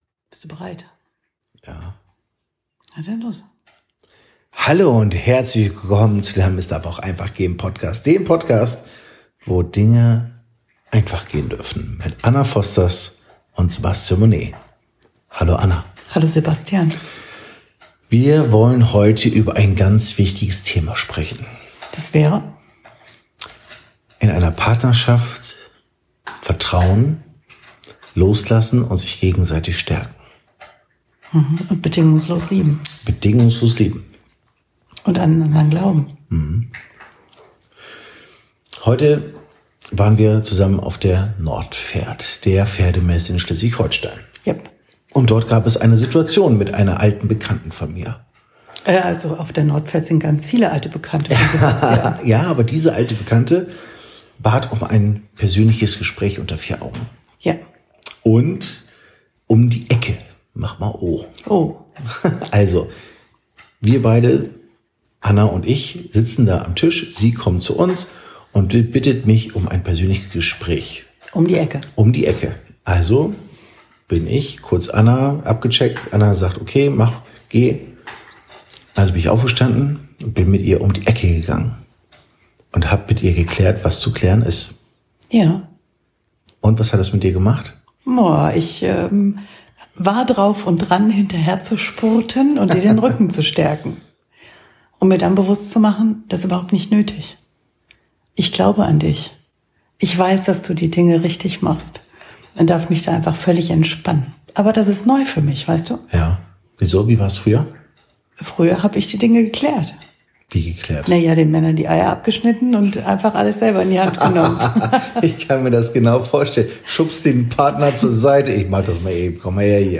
Ein emotionales Gespräch über Vertrauen, Selbstwahrnehmung und die Bedeutung von Vergangenheit in einer Beziehung